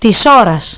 Phrase Übersetzung Phonetik Aussprache
A la carte Της Ώρας tis όras